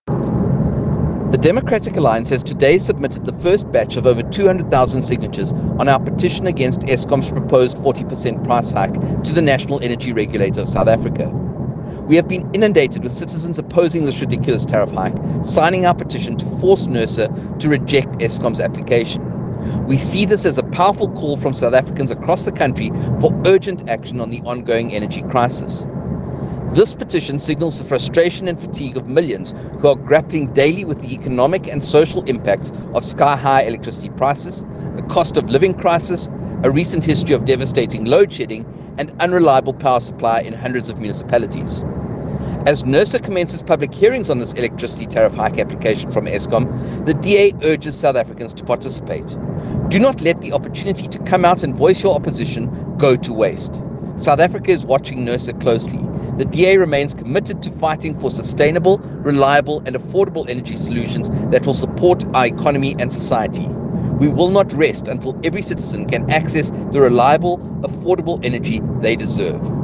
Issued by Kevin Mileham MP – DA Spokesperson on Energy and Electricity
soundbite by Kevin Mileham MP and Youtube link to re-watch the event.
The DA protested outside NERSA’s offices in Pretoria, as hundreds joined us to show their opposition to Eskom’s tariff increase application.